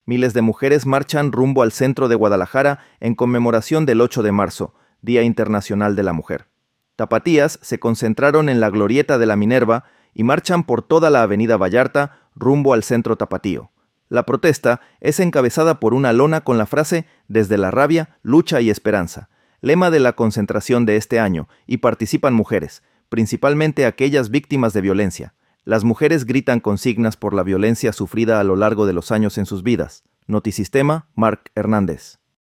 Las mujeres gritan consignas por la violencia sufrida a lo largo de los años en sus vidas.
marcha-1.m4a